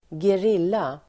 Ladda ner uttalet
Uttal: [²ger'il:a]
gerilla.mp3